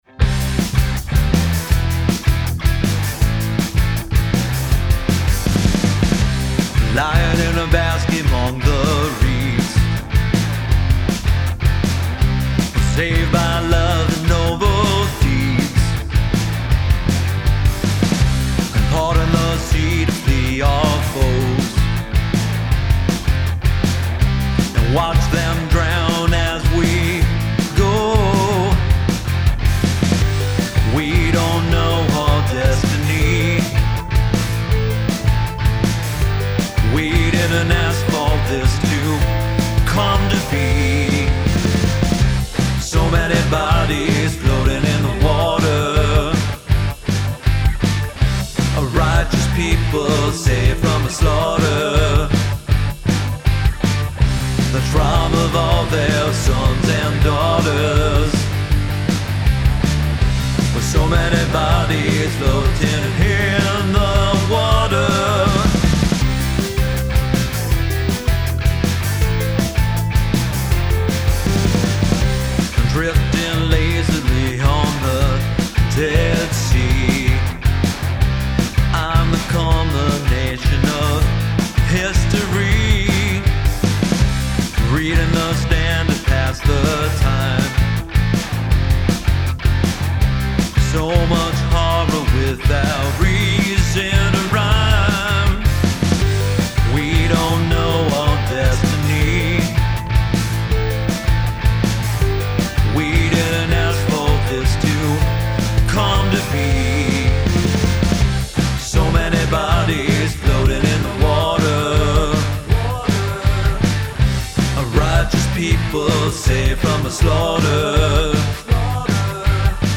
rhythm guitar and bass
drums and keys